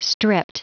Prononciation du mot stripped en anglais (fichier audio)
Prononciation du mot : stripped